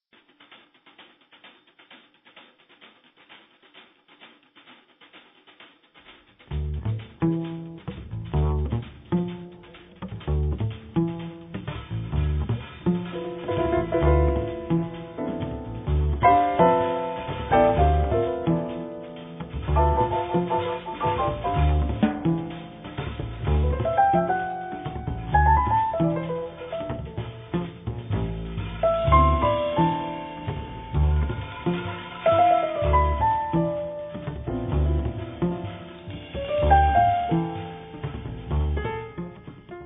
contrabbasso
pianoforte
batteria